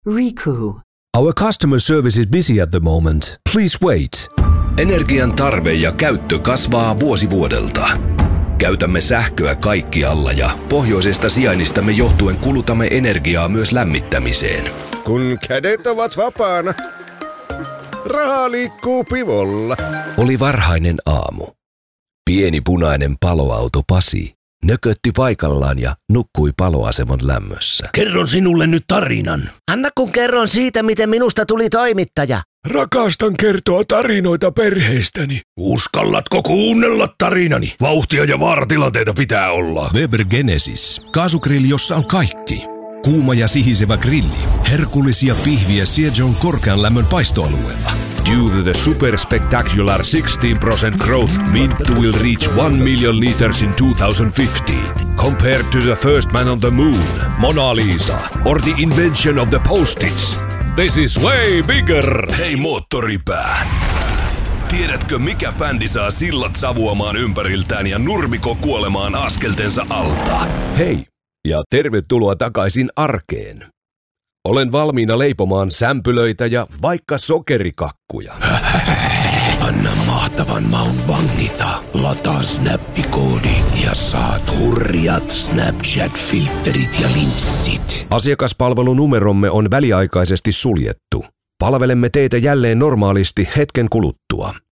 Showcase Demo